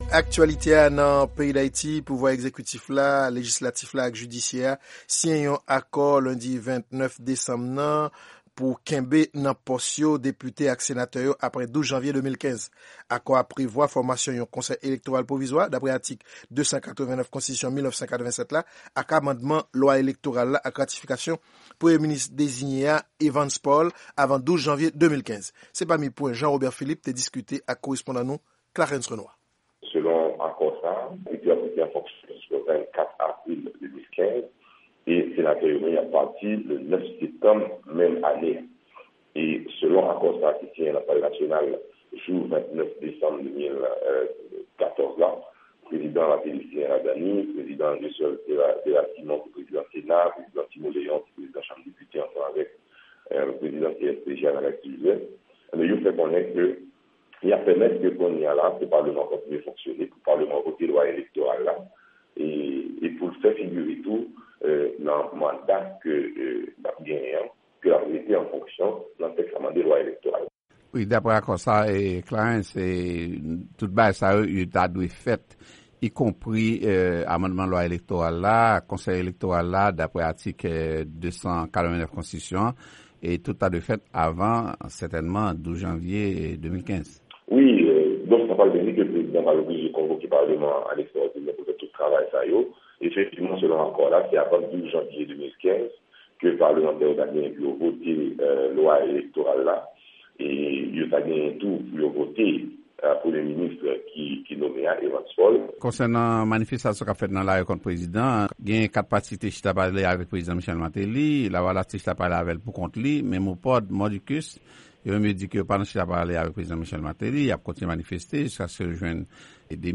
Nan yon entèvyou eksklizif al Lavwadlamerik, senatè a di li menm ak kòlèg li yo pa dakò ak anpil pwen ki nan akò a. Pa egzanp, pou Misye Bien-Aime, delè120 jou akò fikse pou eleksyon dewoule nan peyi a se deja yon siyn ki montre antant politik sa a deja echwe.